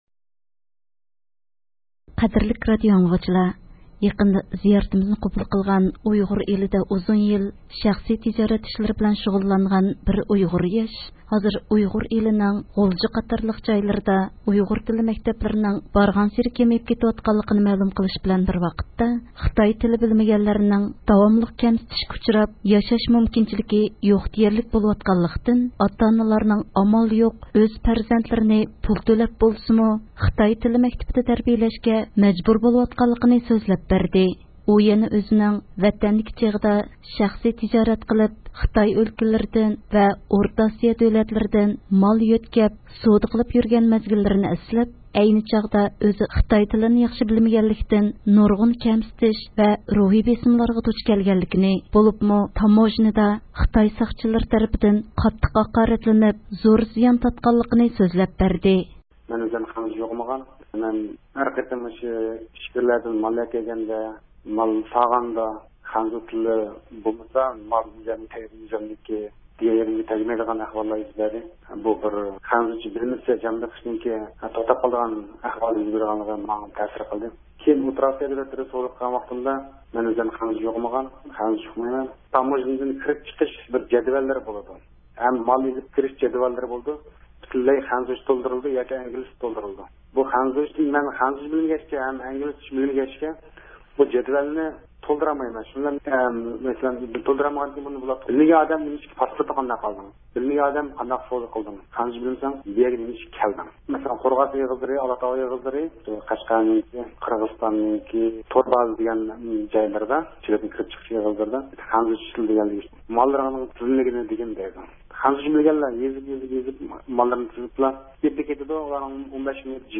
سۆھبىتىدىن